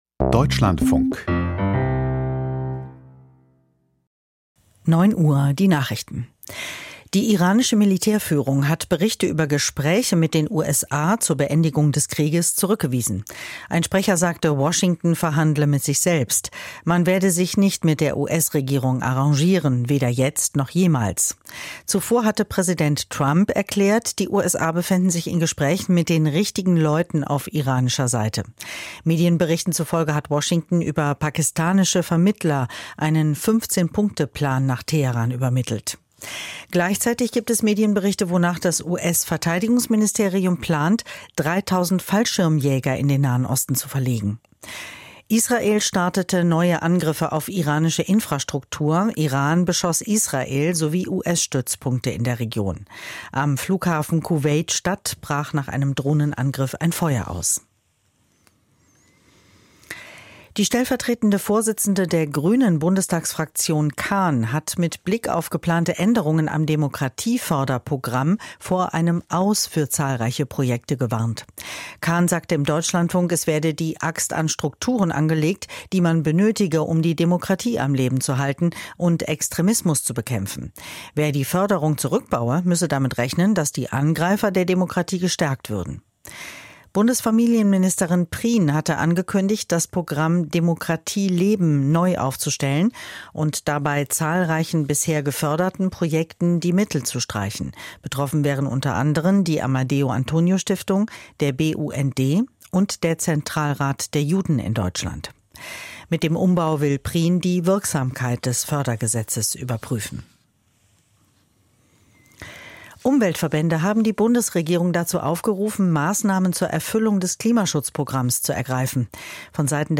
Die Nachrichten vom 25.03.2026, 09:00 Uhr
Aus der Deutschlandfunk-Nachrichtenredaktion.